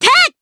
Cecilia-Vox_Attack2_jp.wav